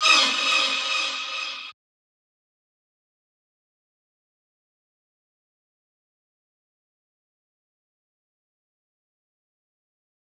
LP Cardiak String Drop.wav